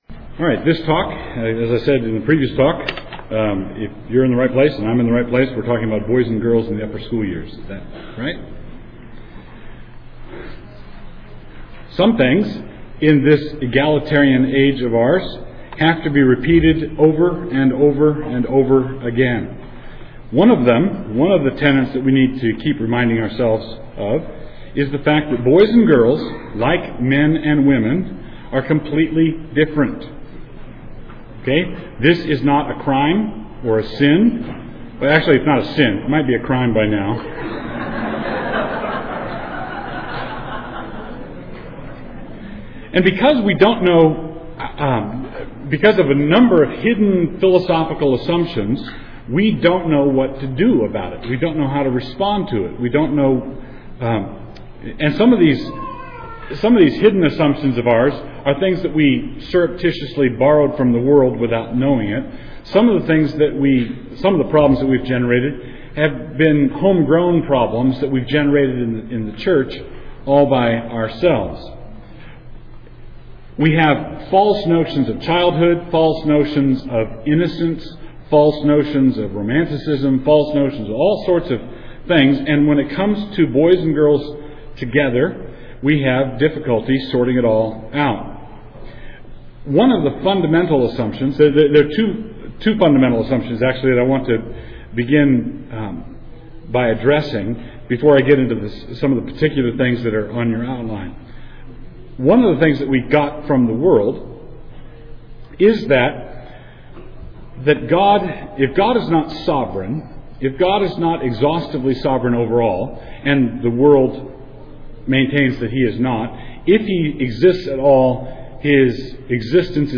2007 Workshop Talk | 0:57:22 | 7-12, Virtue, Character, Discipline
He is the author of numerous books on classical Christian education, the family, and the Reformed faith Additional Materials The Association of Classical & Christian Schools presents Repairing the Ruins, the ACCS annual conference, copyright ACCS.